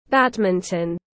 Môn cầu lông tiếng anh gọi là badminton, phiên âm tiếng anh đọc là /ˈbædmɪntən/ .
Badminton /ˈbædmɪntən/